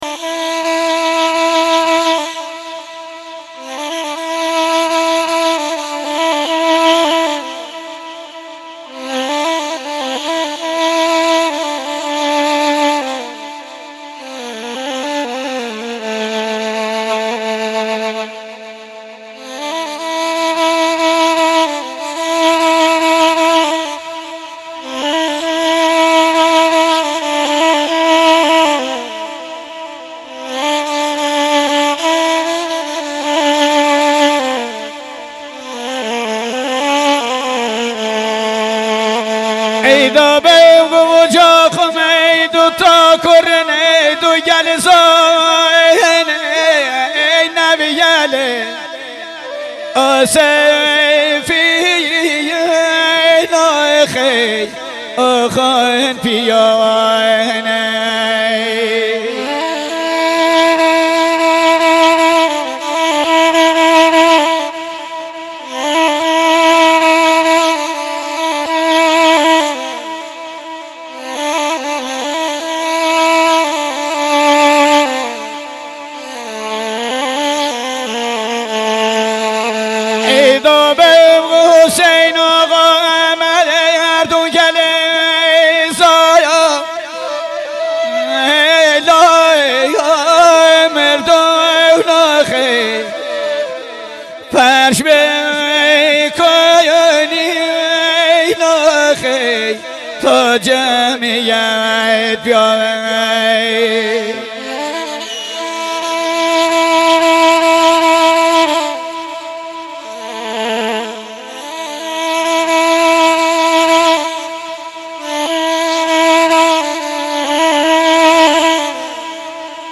دانلود عزاداری و سوگواری لری بختیاری
سبک ها: دندال (دوندال)، گاگریو – گویش: بختیاری